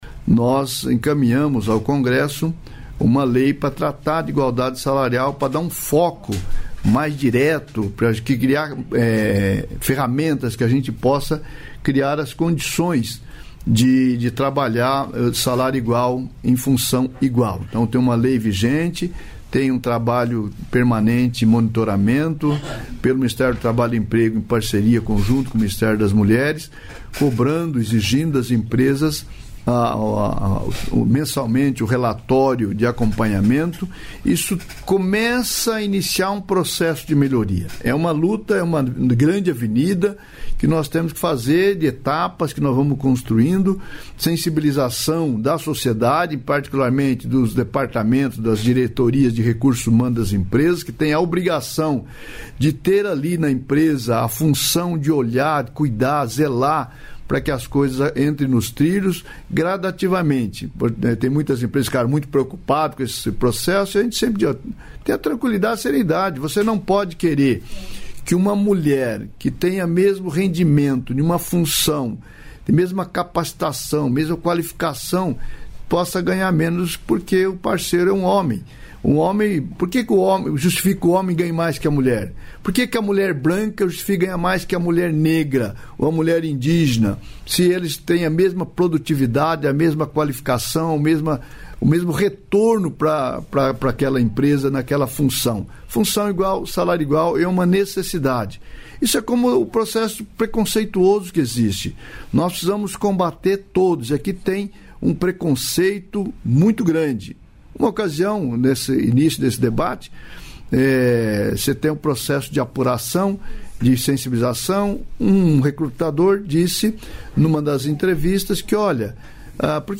Trecho da participação ministro do Trabalho e Emprego, Luiz Marinho, no programa "Bom Dia, Ministro" desta quarta-feira (30), nos estúdios da EBC em Brasília (DF).